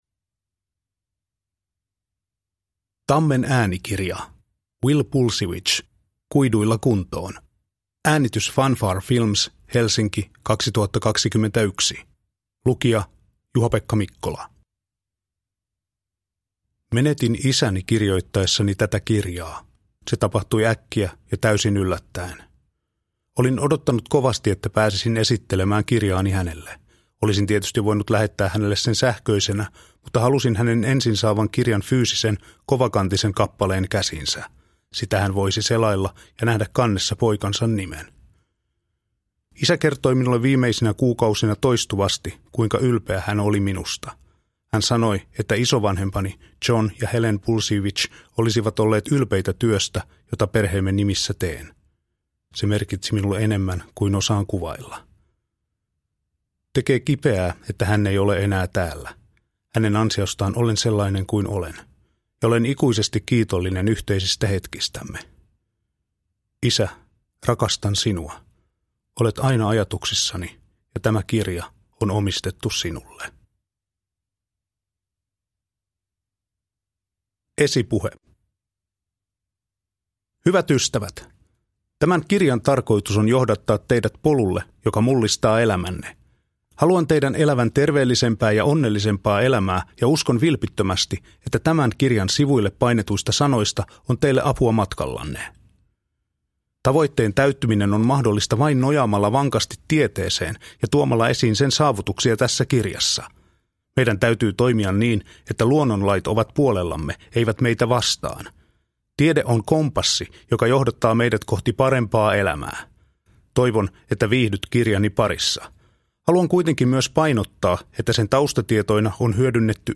Kuiduilla kuntoon – Ljudbok – Laddas ner